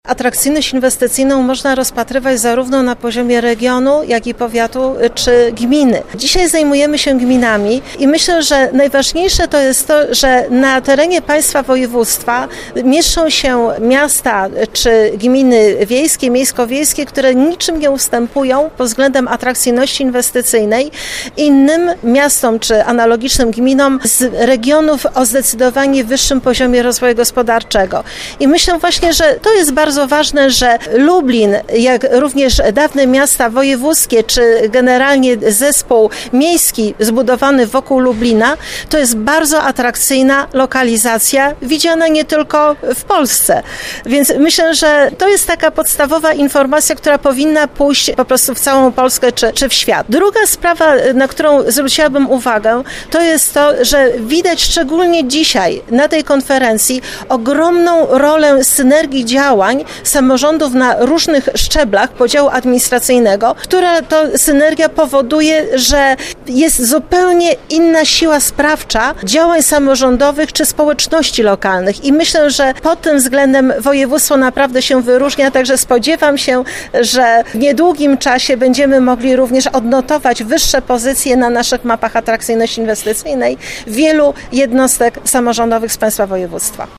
O tym, jak „złapać w sieć” potencjalnego inwestora i dlaczego miasto, gmina czy powiat powinny być dla inwestorów atrakcyjne rozmawiali w środę  (07.03) w Lublinie samorządowcy z całego naszego województwa.